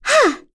Yuria-Vox_Attack2.wav